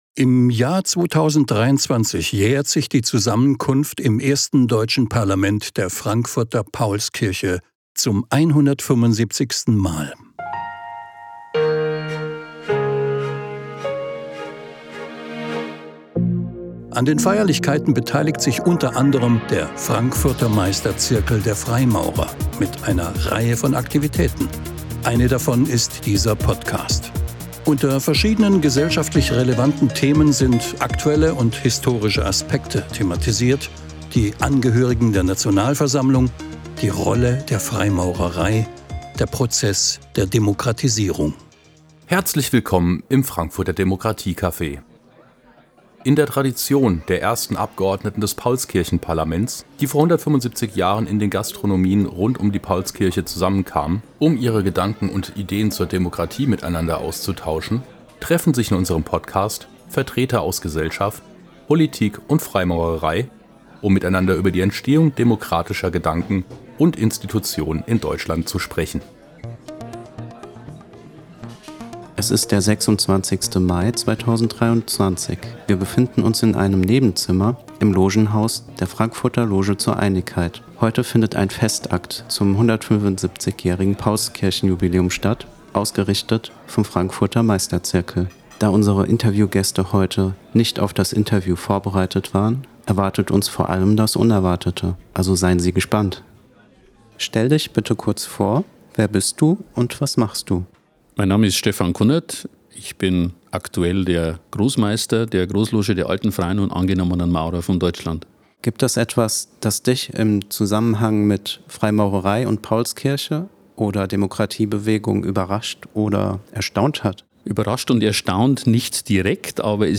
Interviews vom Freimaurer-Festakt in Frankfurt ~ Frankfurter Demokratie-Café - Freimaurer-Interviews Podcast